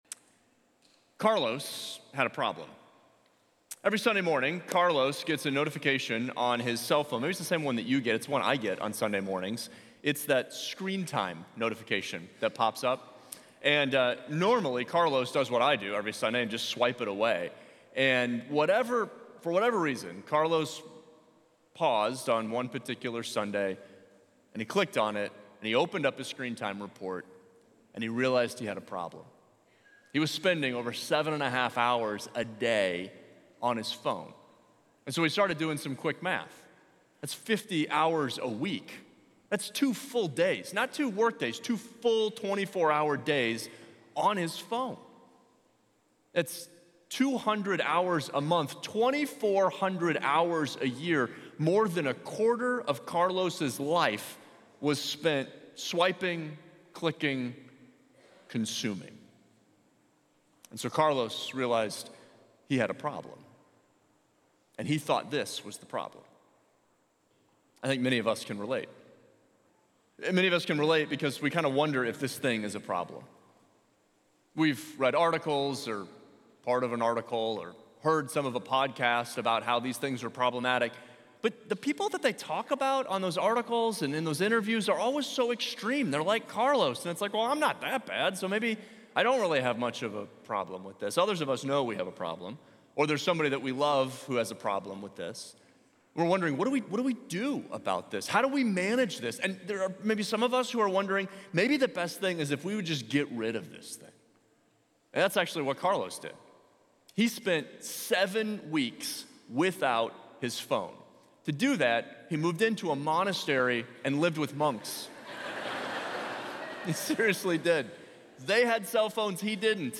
Download guide > Share this Sermon Facebook Twitter Previous Easter: Peace Between Us View Series